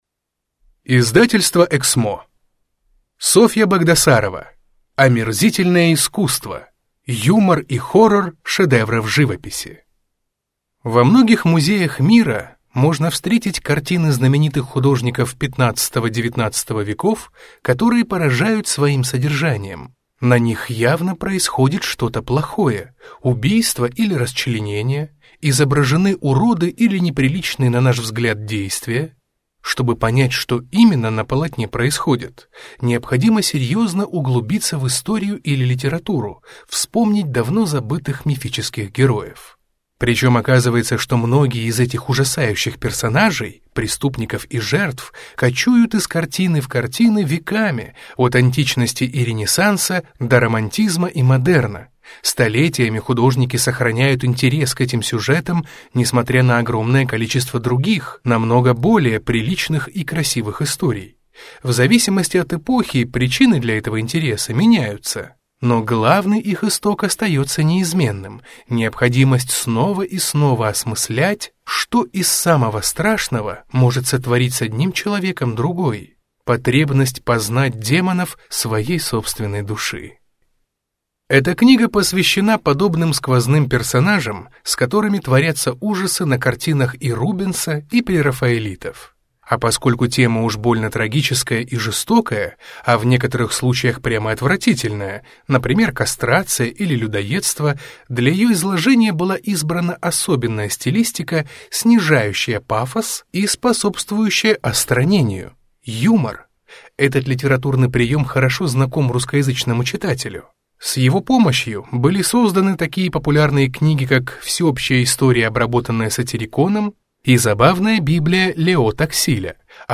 Аудиокнига Омерзительное искусство. Юмор и хоррор шедевров живописи | Библиотека аудиокниг